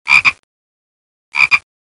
青蛙 | 健康成长
frog-sound.mp3